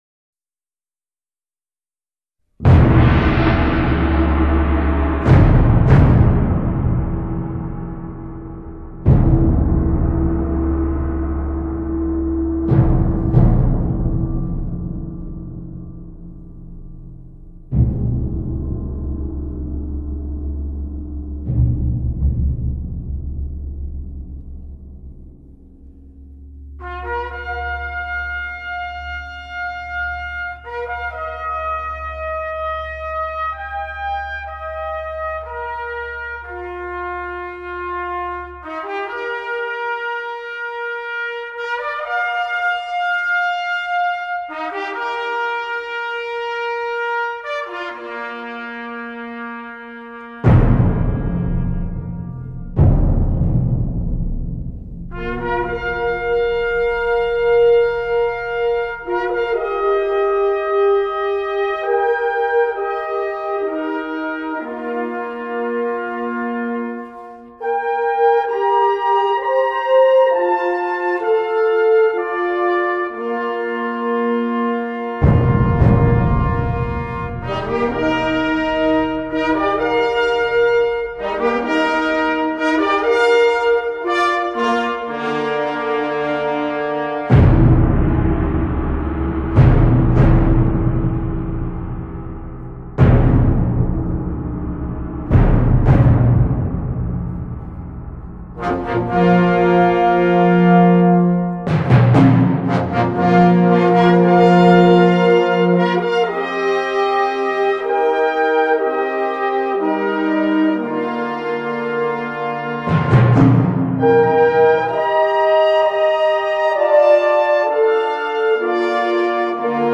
音响绝佳的录音
录音方面，由于采取双轨模拟录音，音质醇美，音色真实，是很值得收藏的一张作品。
最后才在英格兰东南方肯特郡一所已有近300年历史的教堂中，找到心目中理想的地点，来录制这张专辑。
演奏乐器：法国号、长号、大号、定音鼓、低音鼓、铜锣